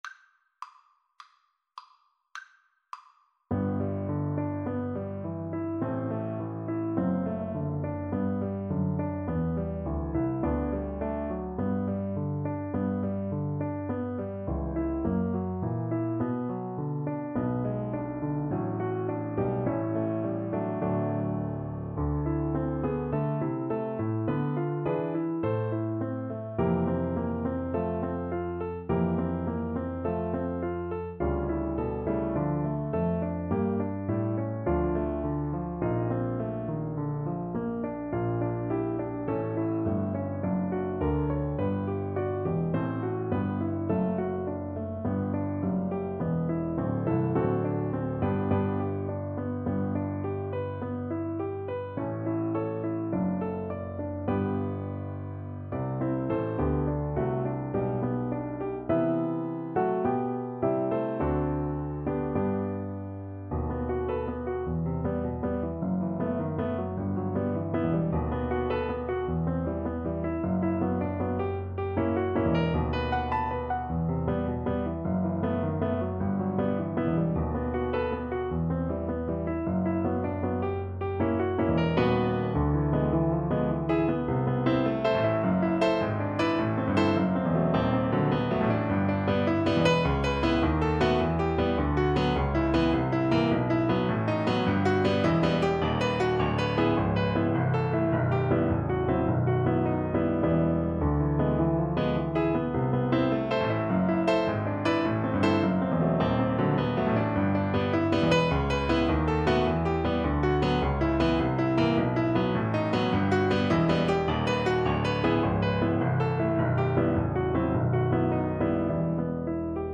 Play (or use space bar on your keyboard) Pause Music Playalong - Piano Accompaniment Playalong Band Accompaniment not yet available transpose reset tempo print settings full screen
G major (Sounding Pitch) A major (Clarinet in Bb) (View more G major Music for Clarinet )
4/4 (View more 4/4 Music)
Einfach, innig =104
Classical (View more Classical Clarinet Music)